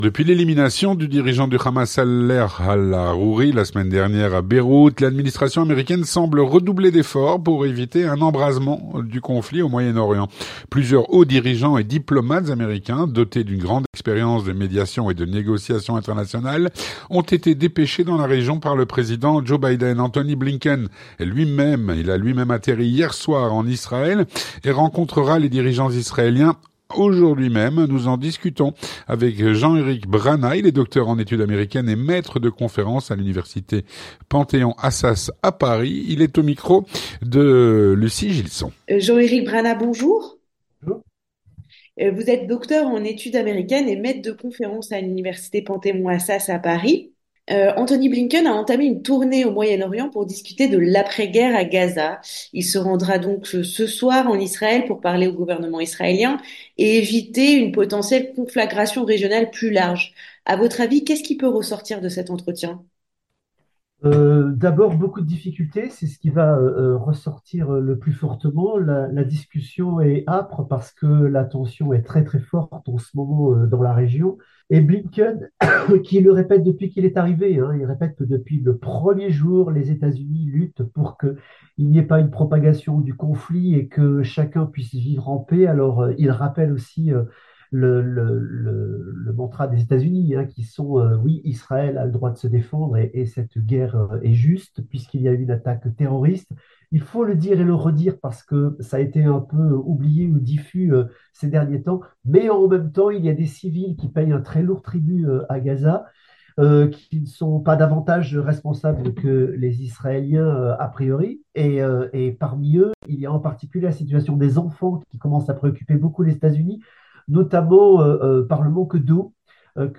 L'entretien du 18H - L'administration américaine redouble d'efforts pour éviter un embrasement du conflit au Moyen-Orient.